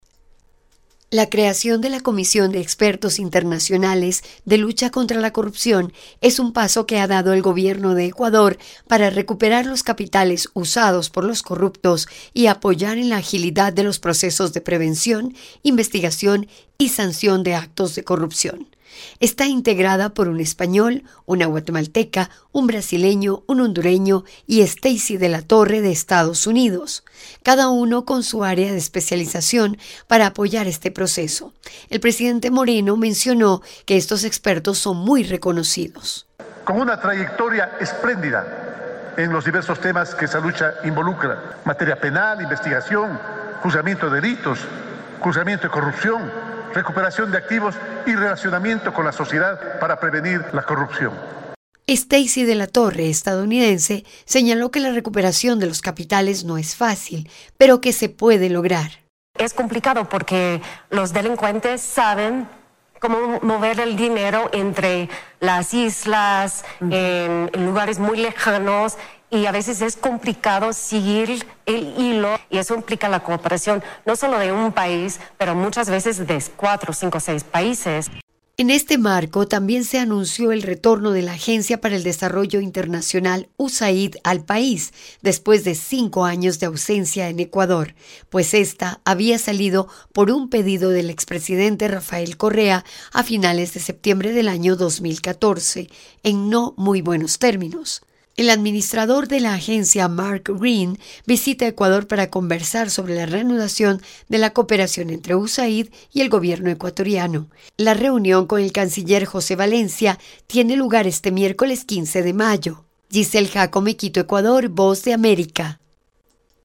VOA: Informe desde Ecuador